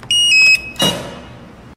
keypadCheck.wav